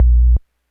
kick05.wav